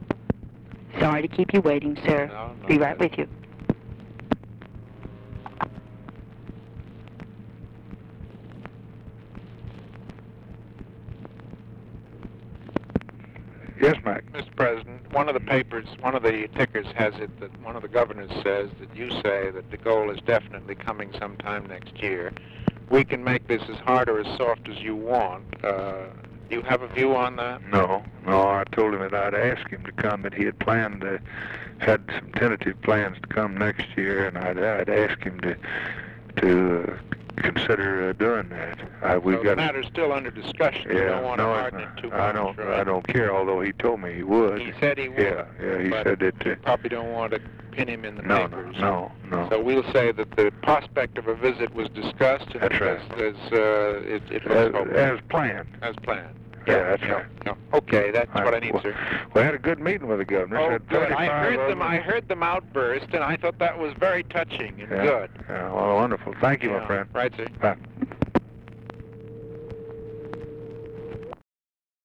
Conversation with MCGEORGE BUNDY, November 26, 1963
Secret White House Tapes